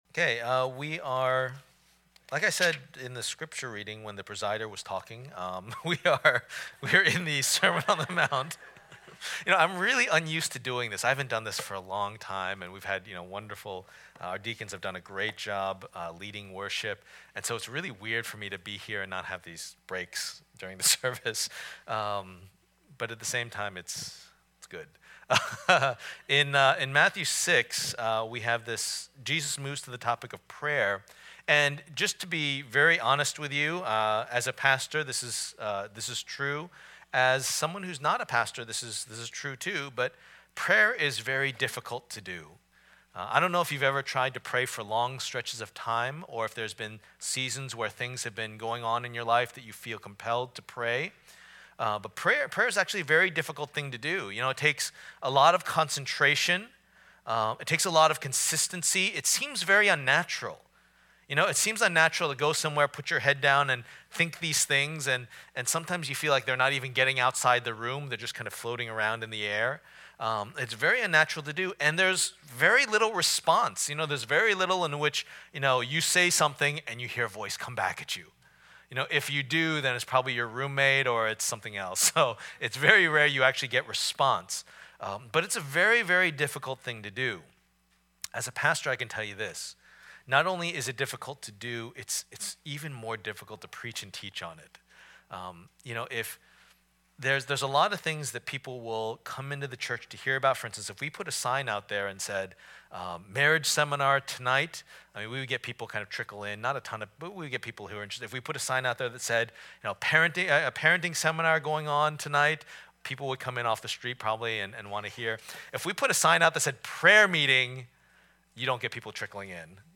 Life in God's Kingdom Passage: Matthew 6:5-15 Service Type: Lord's Day %todo_render% « How to Alleviate Anxiety?